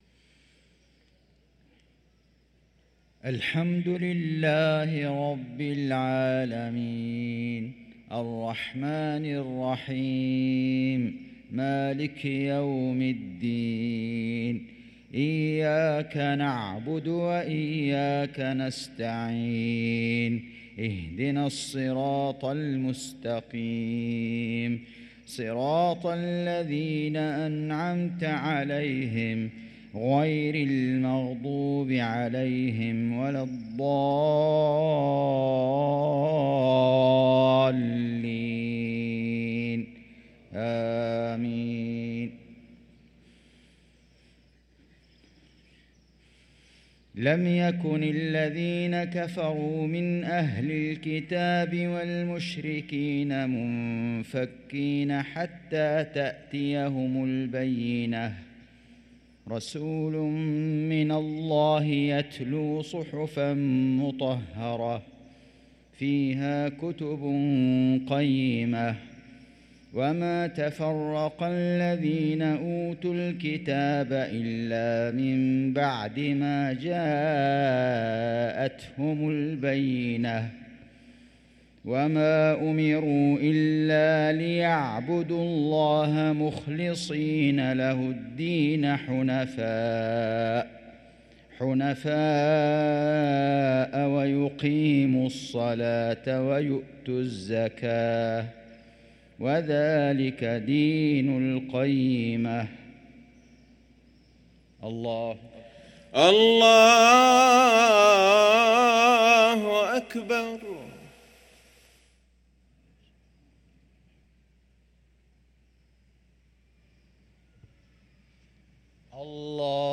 صلاة المغرب للقارئ فيصل غزاوي 27 ربيع الآخر 1445 هـ
تِلَاوَات الْحَرَمَيْن .